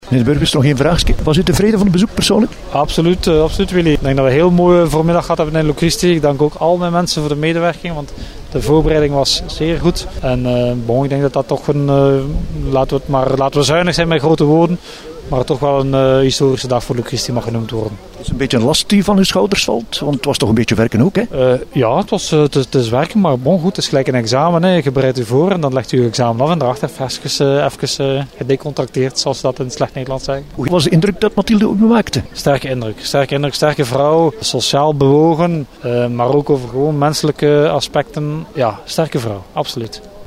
Wel bereid gevonden tot een gesprekje, na afloop,  was burgemeester Yves Deswaene.